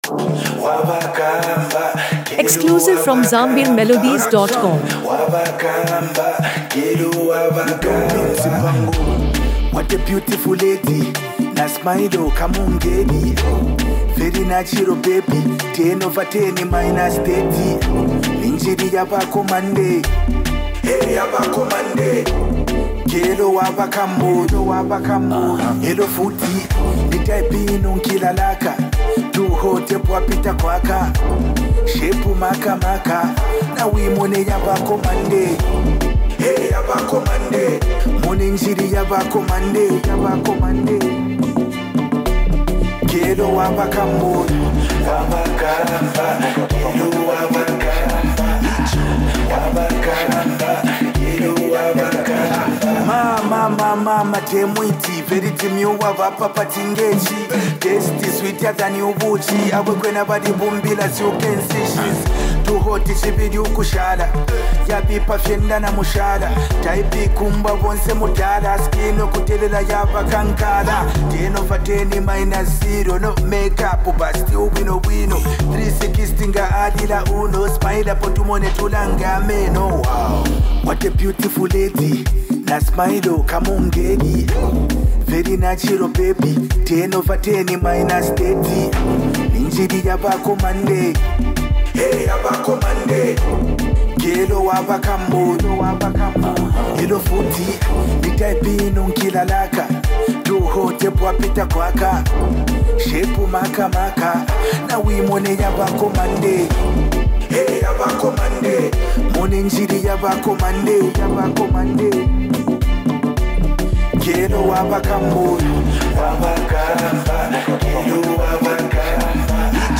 Lusaka Club Banger
is an energetic club banger